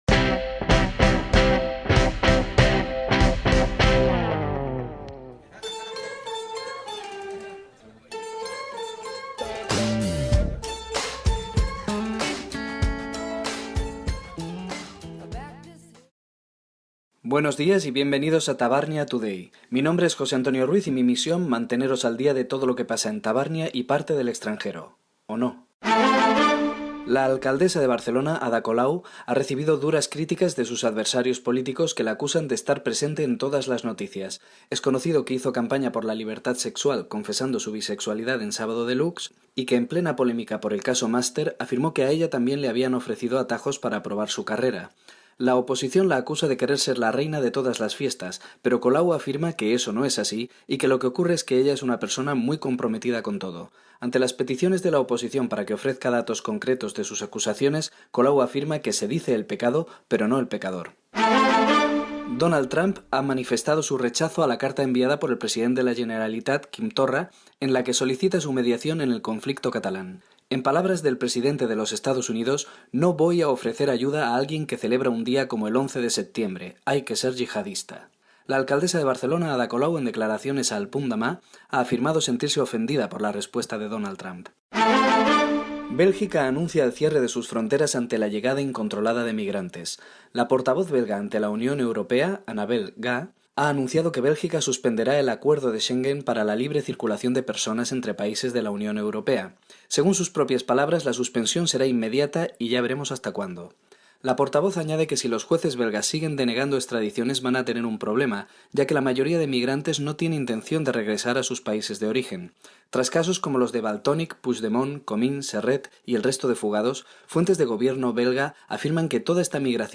Notícies satíriques sobre Ada Colau, les extradicions belgues, Festival de Sitges 2018, correus entre els presidents de la Generalitat Torra i Puigdemont, concert Operación Triunfo 2017, Clara Ponsatí
Entreteniment